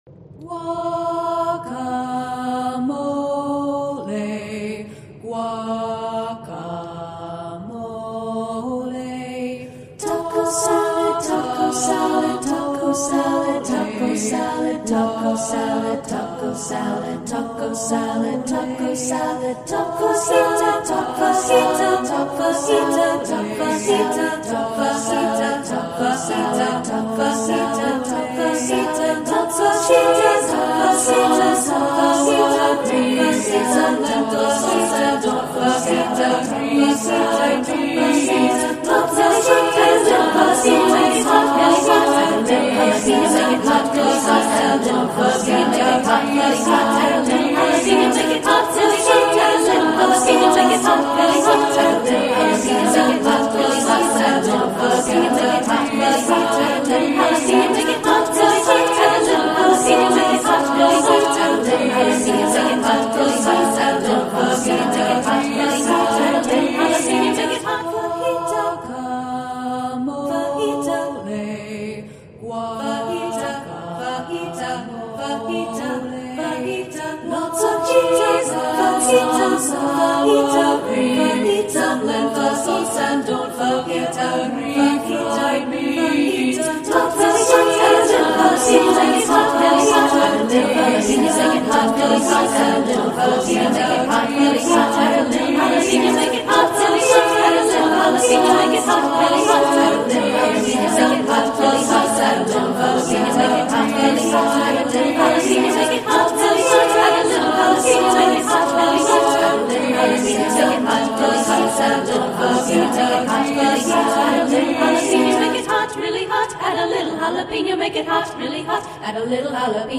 Taco Bell Canon by Concordia Chorus Practice Audio from the album Spring 2025
Taco-Bell-Canon-Acapella.mp3